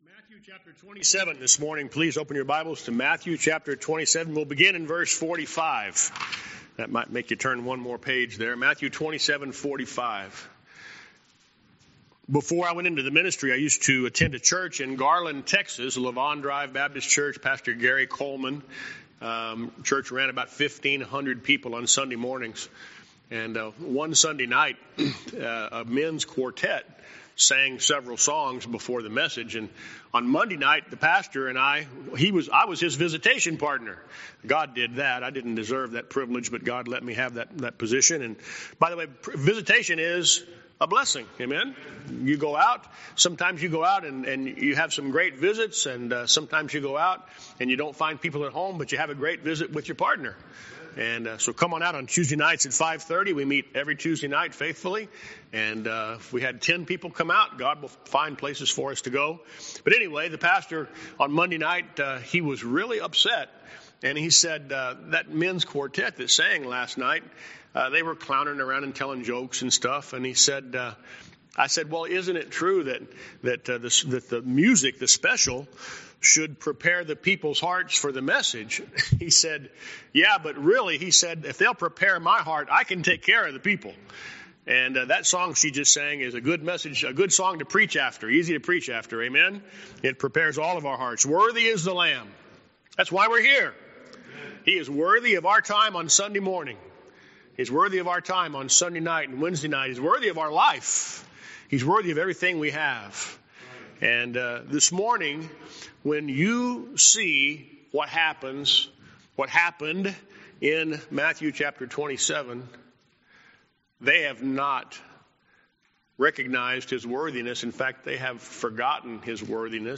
Sermon Recordings